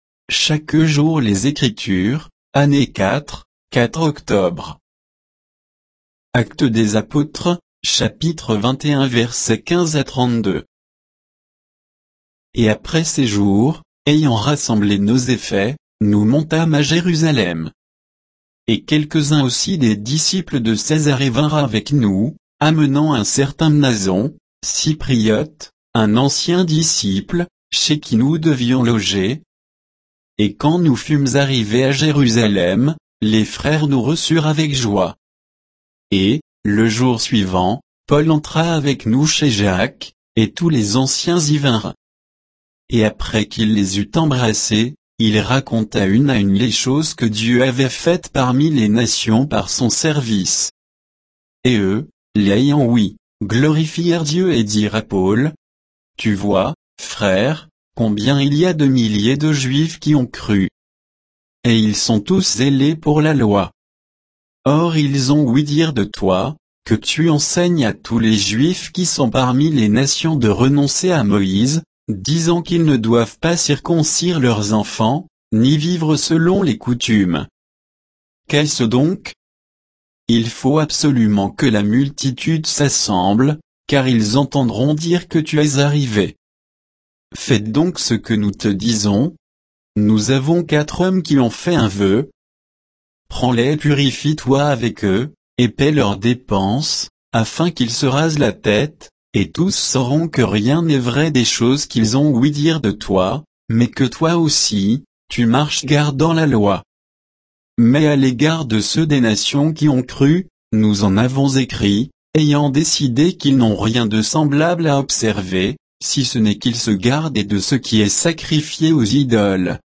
Méditation quoditienne de Chaque jour les Écritures sur Actes 21, 15 à 32